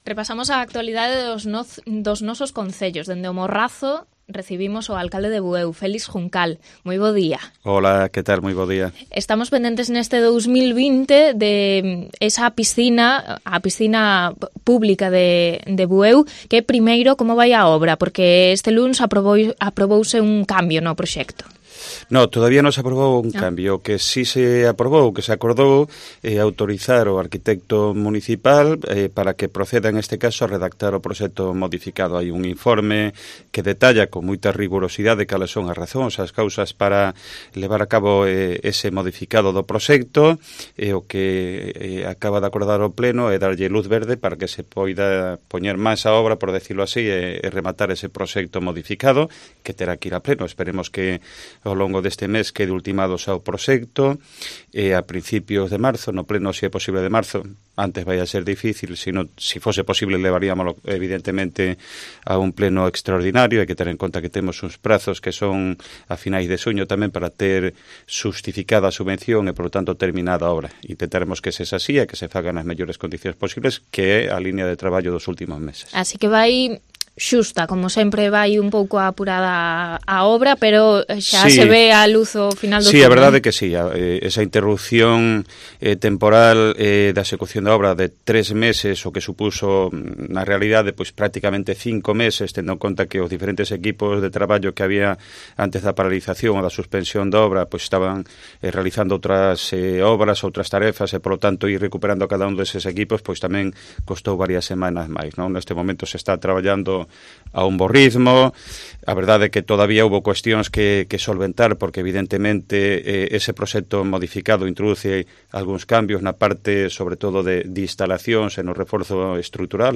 Entrevista en COPE al alcalde de Bueu